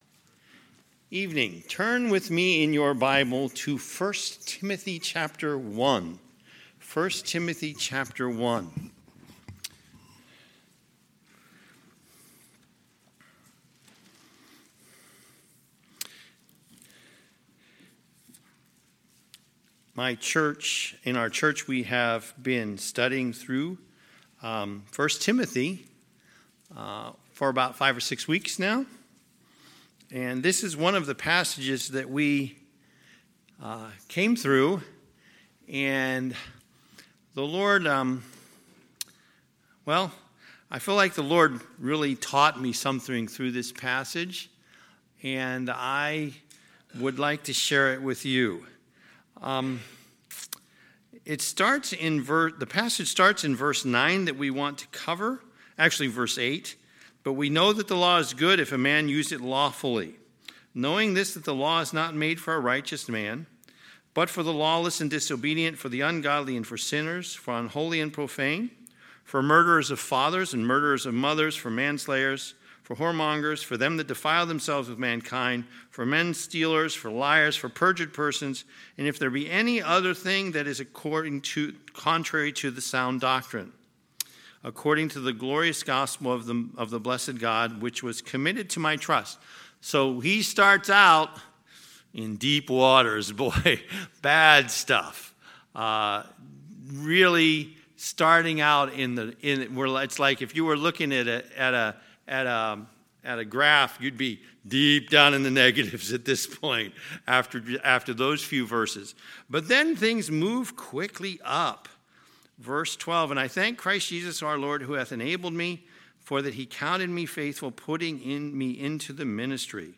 Sunday, September 19, 2021 – Sunday PM